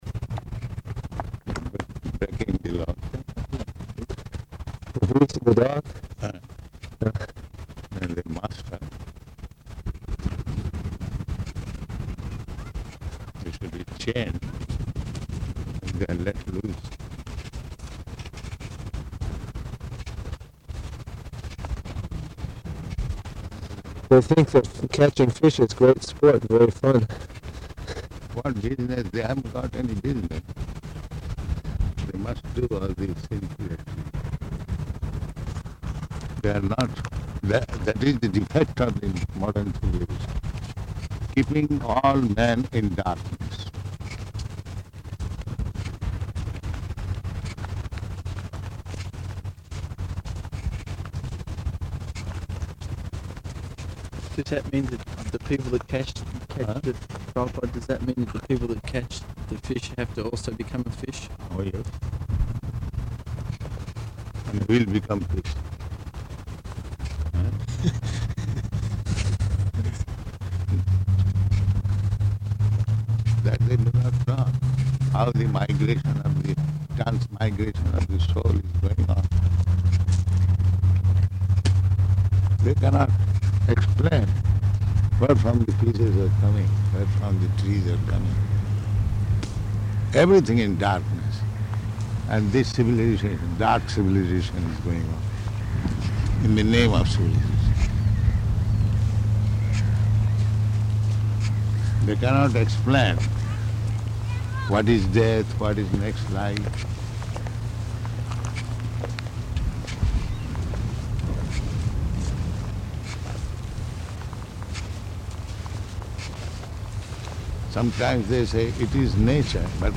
Morning Walk on Beach
Morning Walk on Beach --:-- --:-- Type: Walk Dated: May 9th 1975 Location: Perth Audio file: 750509MW.PER.mp3 [Poor audio for first 1:30 Minutes] Prabhupada: Breaking the law.